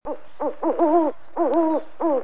Le hibou | Université populaire de la biosphère
il brait
hibou.mp3